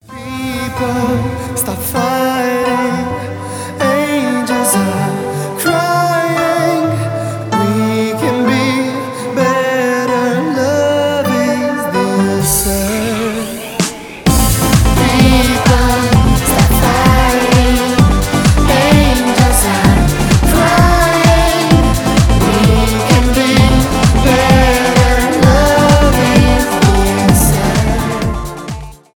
танцевальные
dance pop
progressive house